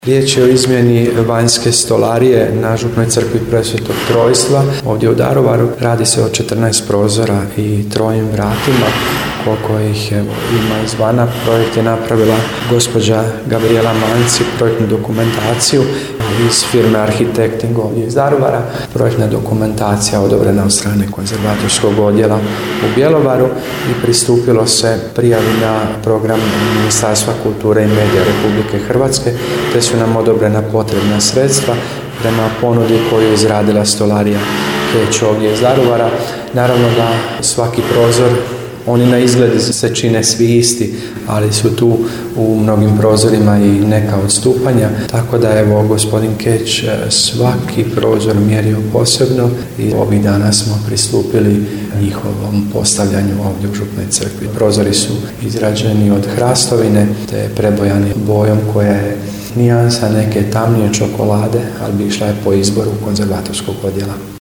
Prije nekoliko dana započela je izmjena drvene stolarije na župnoj crkvi Presvetog Trojstva u Daruvaru. U akustičnom prostoru i bušilica nekako melodično zvuči, tako da je upravo taj radni prostor unutar crkve bio teren s kojeg prenosimo riječi, zvukove, radnu energiju…